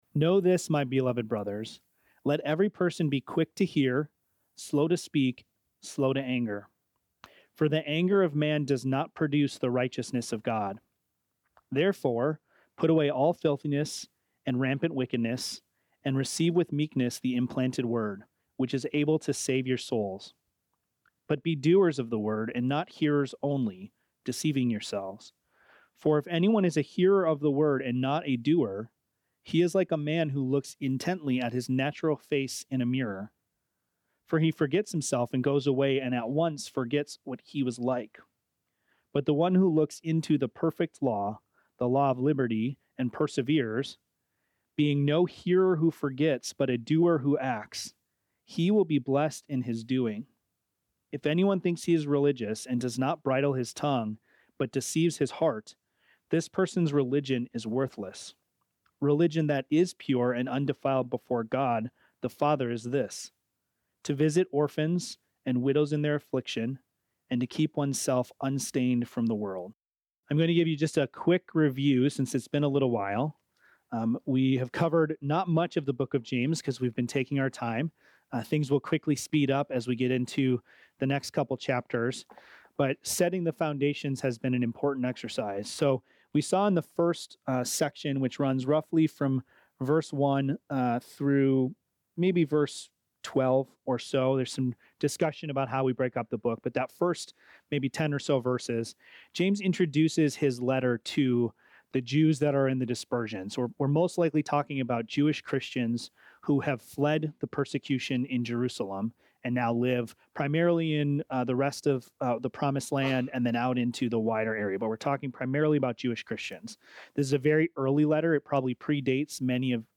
The sermon contrasts two types of people: those who hear the Word but fail to act on it, and those who hear and do the Word, demonstrating their faith through transformation and good works.